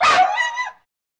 Index of /90_sSampleCDs/E-MU Producer Series Vol. 3 – Hollywood Sound Effects/Human & Animal/Dogs
AGITATED 04R.wav